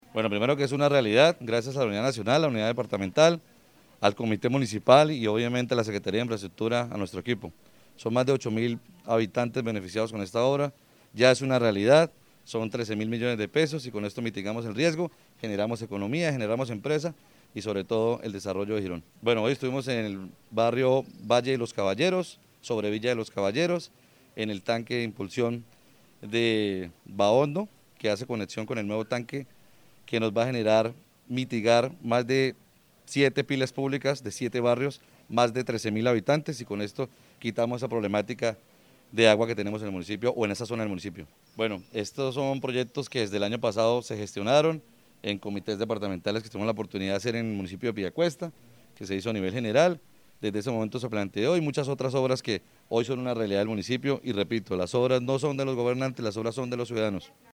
Carlos-Román-Alcalde-de-Girón.mp3